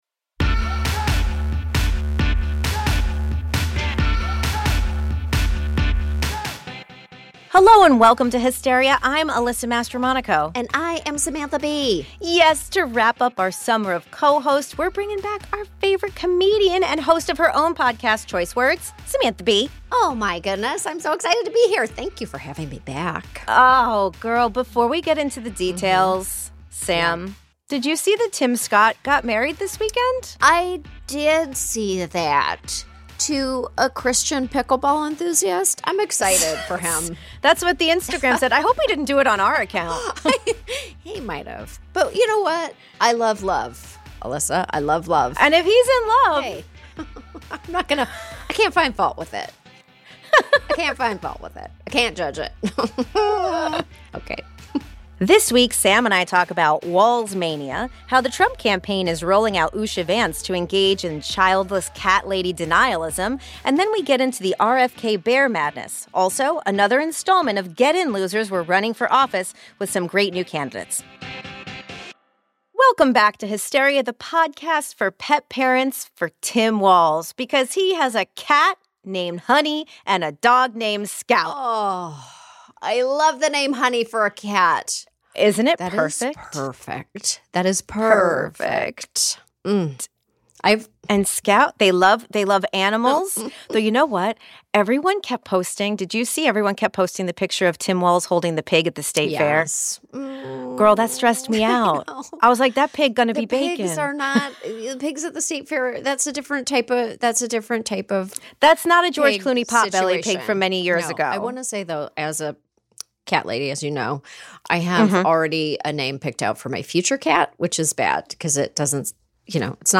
Samantha Bee is back to co-host with Alyssa one last time! They discuss Tim Walz joining the Harris 2024 campaign, Usha Vance’s response to her husband JD’s “childless cat lady” comment, and more in news. Then, in Sani-Petty, Sam and Alyssa ponder RFK Jr.’s dead bear in Central Park (the brain worm is real).